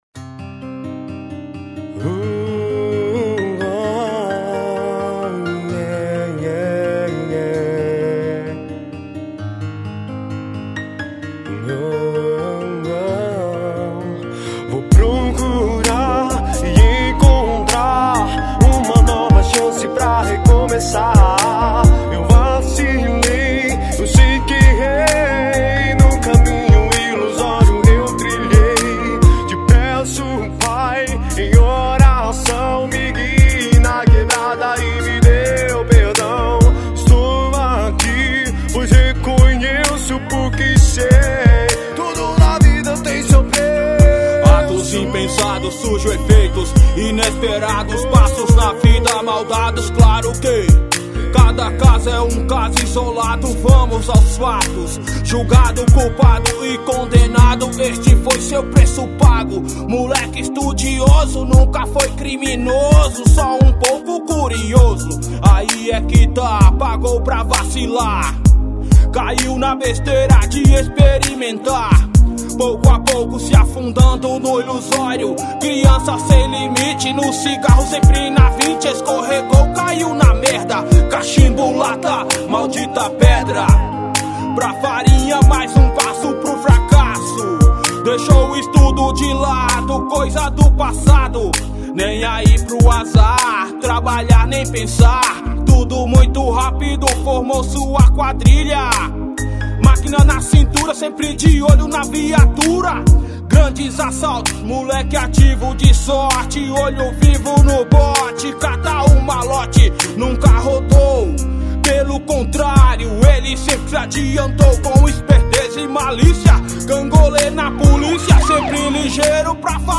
back vocal